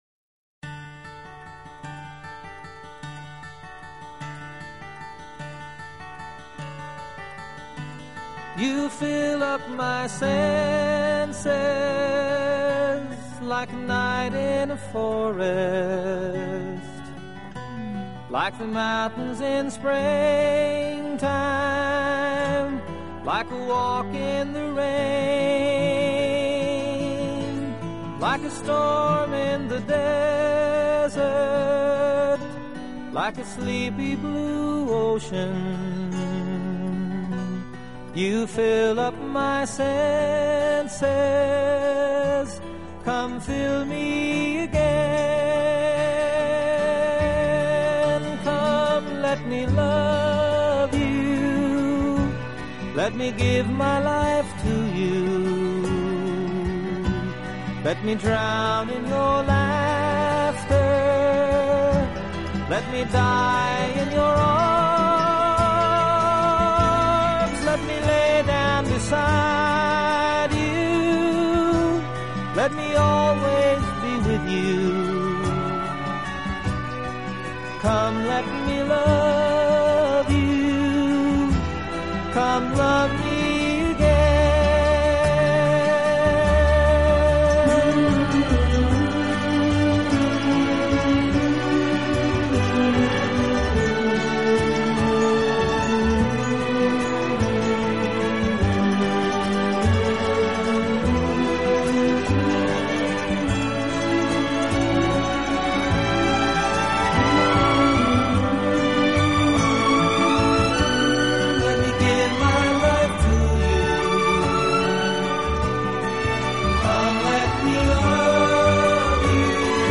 当您在聆听浪漫音乐的时候，优美，舒缓的音乐流水一样缓缓抚过心田，你会觉